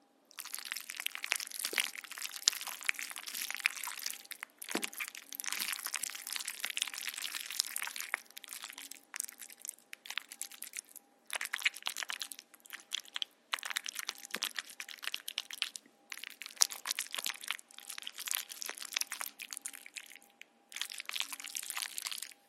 Звук каши с маслом под ложкой